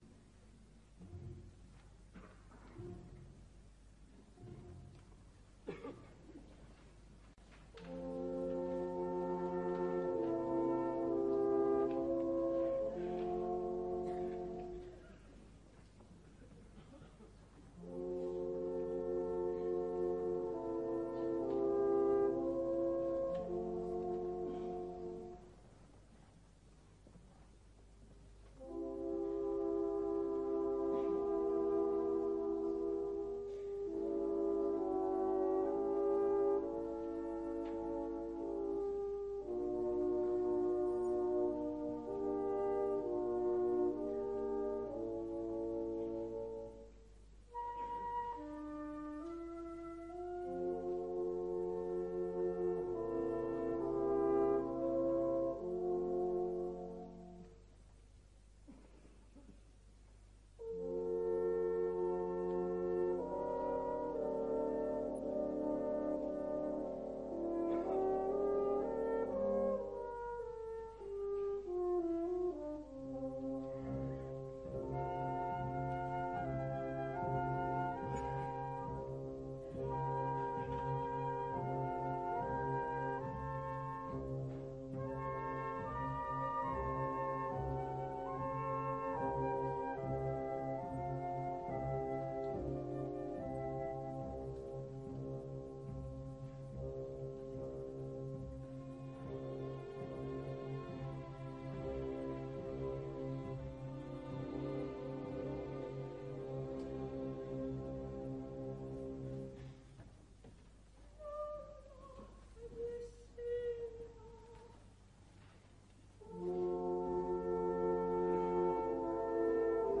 Orchestra del Teatro La Fenice di Veneziadiretta da Vittorio Gui.